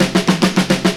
FILL 3    -L.wav